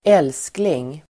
Uttal: [²'el:skling]